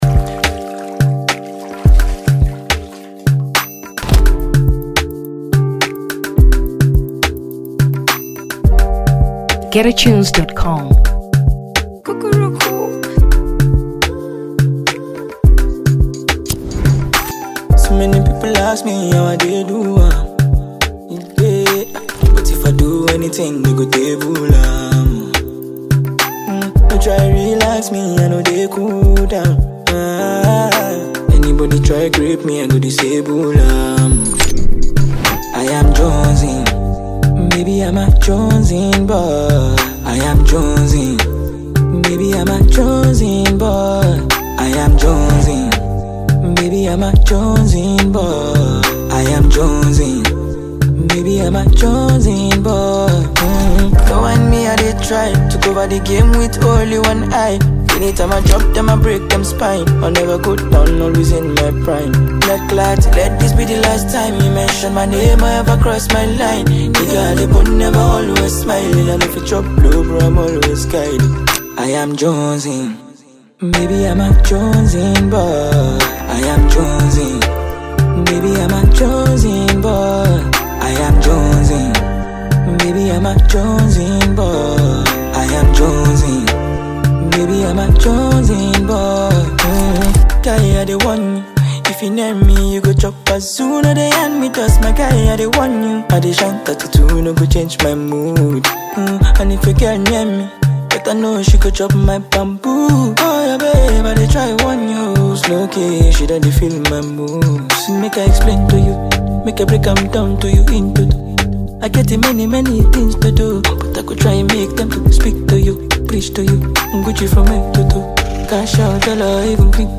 Afrobeats 2023 Nigeria